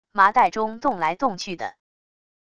麻袋中动来动去的wav音频